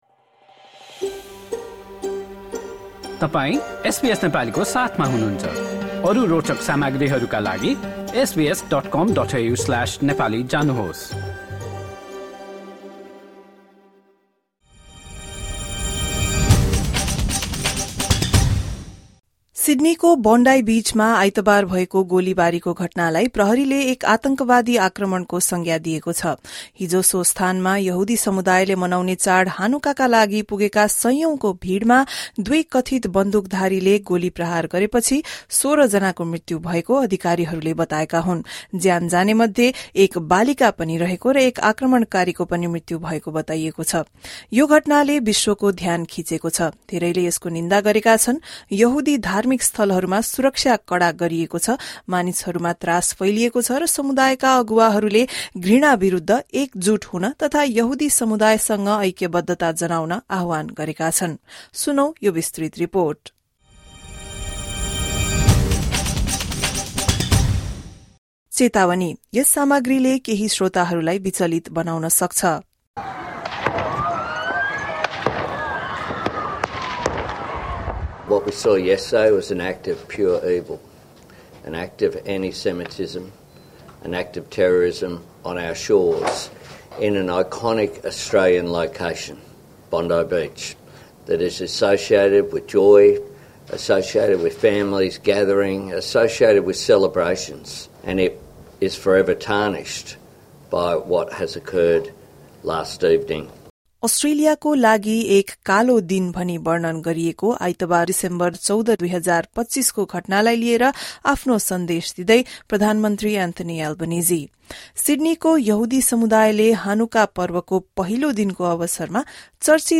एक रिपोर्ट।